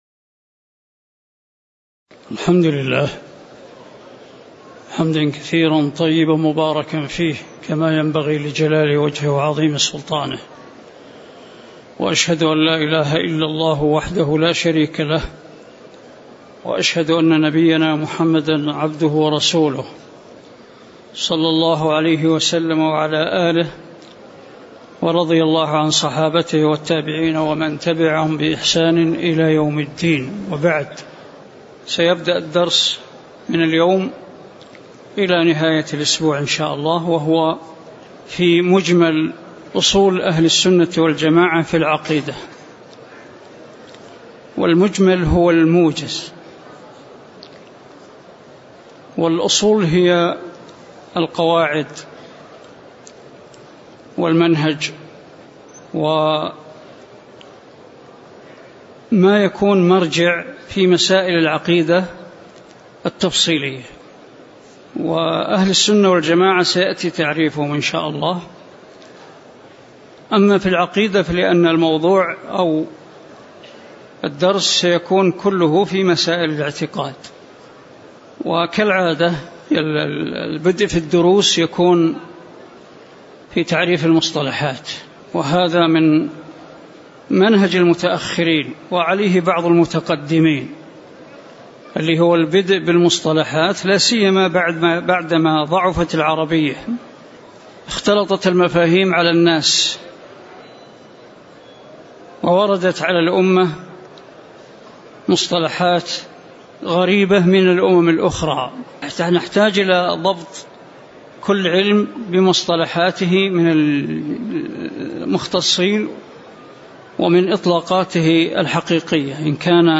تاريخ النشر ٢٦ ربيع الثاني ١٤٣٩ هـ المكان: المسجد النبوي الشيخ